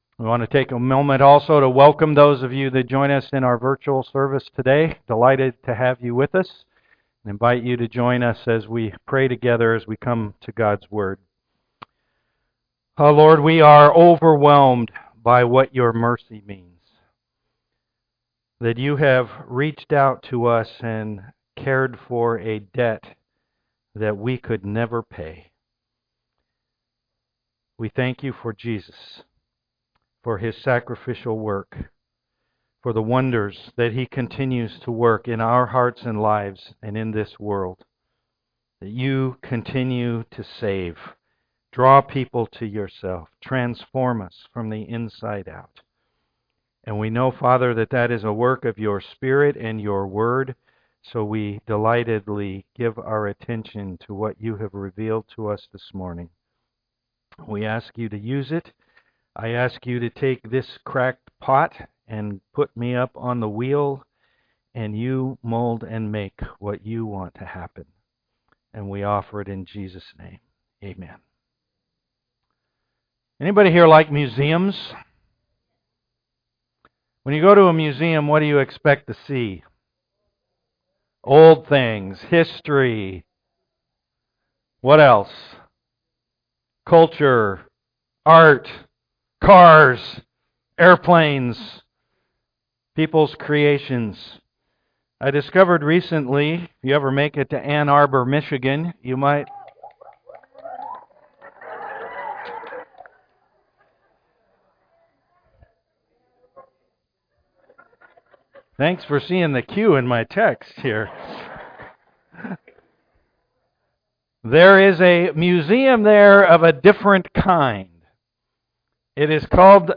Romans 9 Service Type: am worship Paul declares the Good News about Jesus was to the Jews first.